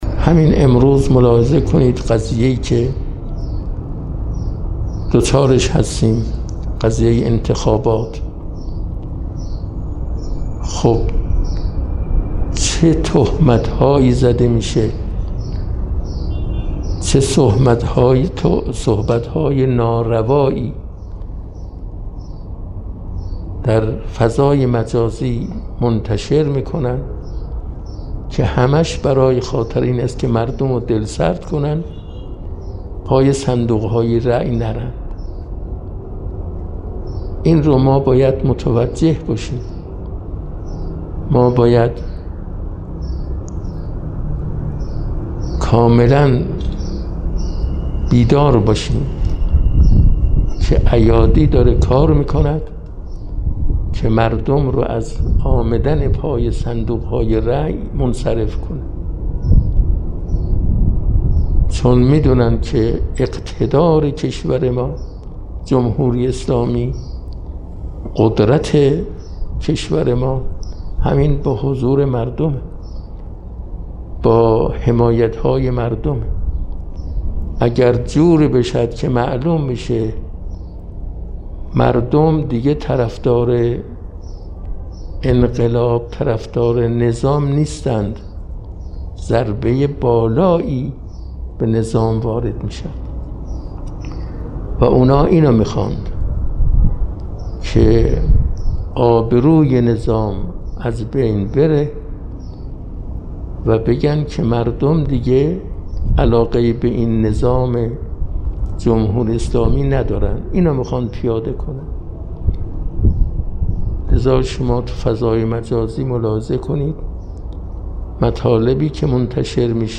به گزارش خبرنگار ایکنا، آیت‌الله مرتضی مقتدایی، رئیس شورای سیاستگذاری حوزه‌های علمیه خواهران امروز بعد از جلسه درس خود در بیت امام خمینی (ره) در قم، با اشاره به انتخابات پیش رو و تهمت‌ها و صحبت‌های ناروایی که به نیت دلسرد کردن مردم نسبت به انتخابات در فضای مجازی منتشر می‌شود، اظهارکرد: این برنامه‌ها با هدف این که مردم پای صندوق‌های رأی حاضر نشوند، از سوی دشمنان طراحی می‌شود و ما باید نسبت به این موضوع متوجه و بیدار باشیم.